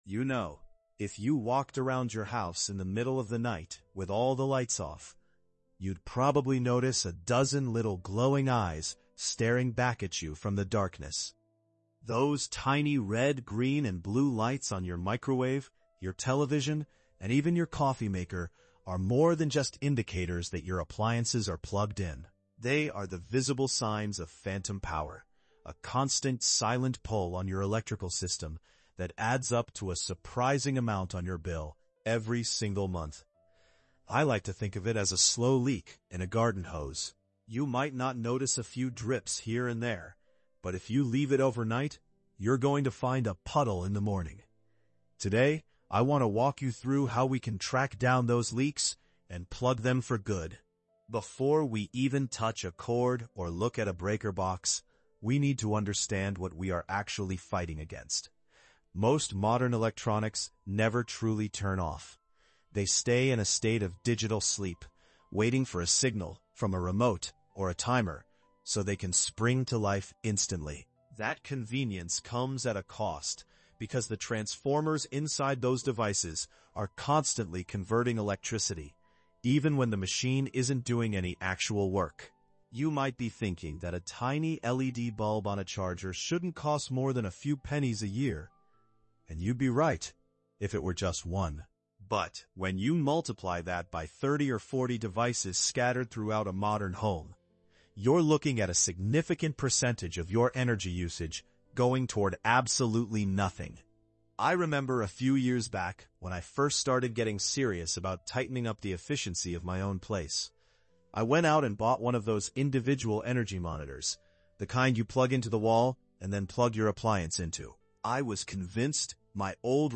Whether you are a new homeowner or just looking to trim the fat off your monthly expenses, this conversational walkthrough provides the blue-collar skills you need to maintain a lean, high-performing home.